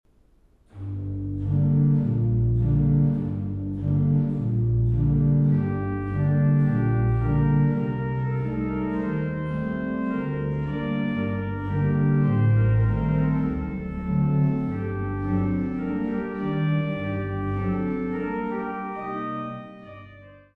Walcker-Orgel St. Jakobus zu Ilmenau